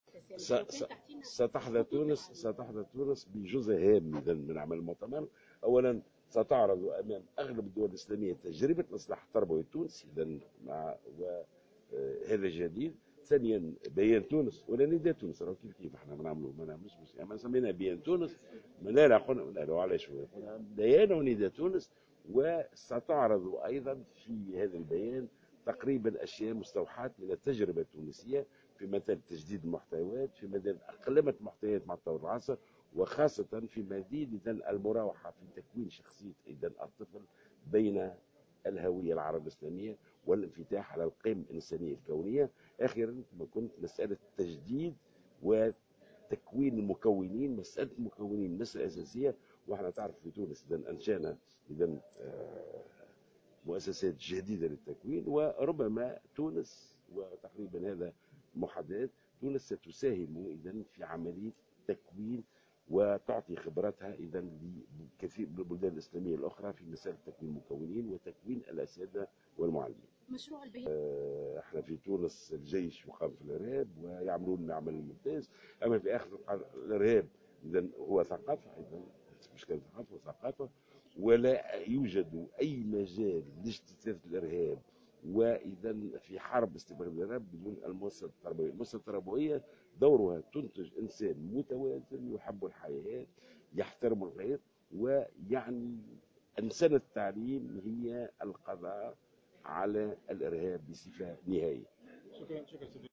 وأضاف في تصريحات صحفية على هامش انعقاد مؤتمر المنظمة الاسلامية للتربية والعلوم والثقافة (الايسيسكو) لوزراء التربية في تونس، أنه تم إنشاء مؤسسات تكوين جديدة في تونس وستساهم تونس من خلالها في عملية تكوين المكونين والأساتذة والمعلمين بالدول الإسلامية.